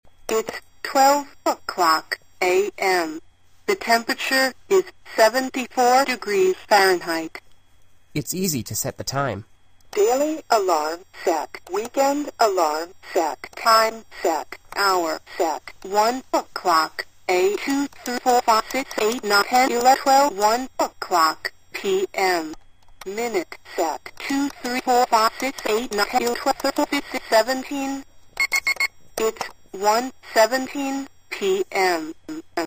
Deluxe Model, with Large Display and Human Voice
• Features crystal-clear female voice and 1" high numbers on a huge, brightly-lit digital LCD display.
By simply touching the four-inch long TALK button on top, it announces the time and indoor temperature (in Fahrenheit or Celcius) with a pleasant female voice.
talking_thermometer_clock.mp3